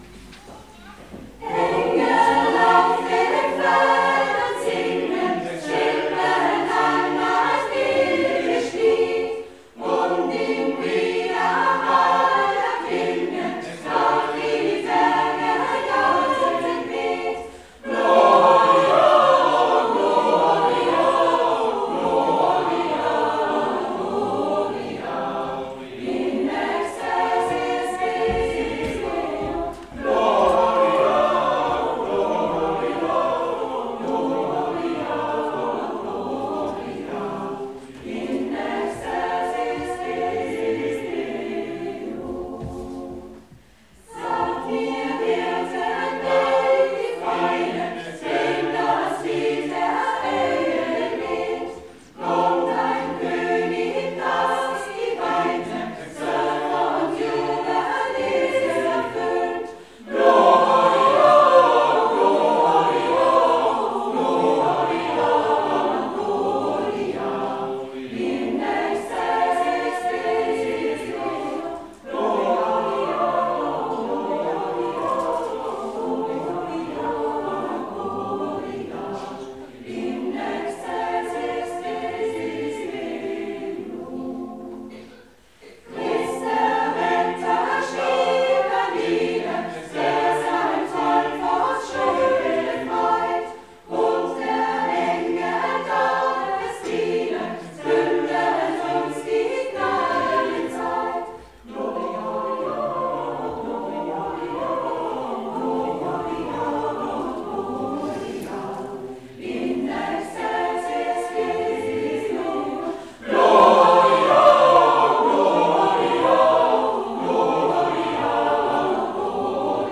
gesungen vom Vokalensemble MOSAIK live im Buchensaal Puchenau: